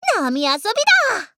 贡献 ） 协议：Copyright，其他分类： 分类:雪之美人语音 您不可以覆盖此文件。